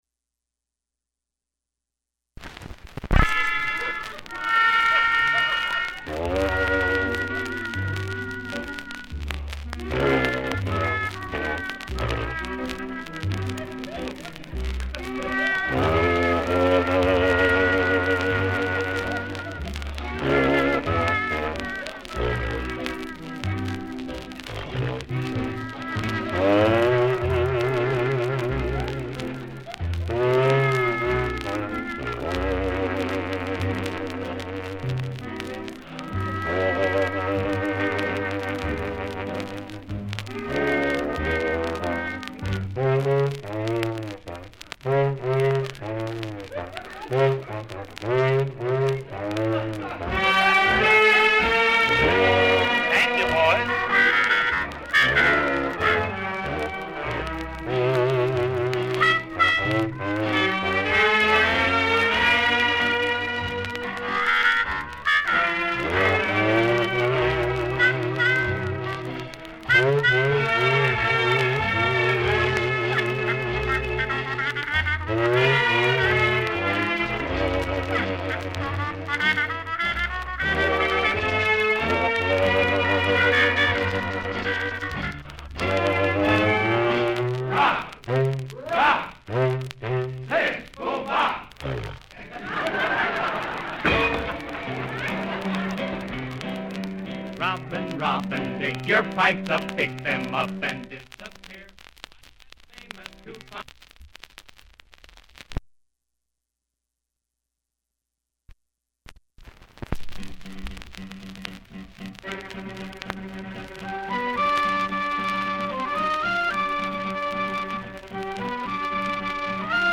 Burns’ signature instrument—the bazooka, a homemade contraption fashioned from gas pipes and a whiskey funnel—became an iconic part of his act, earning laughs from audiences nationwide.
Recording of Bob Burns playing the bazooka, 1938.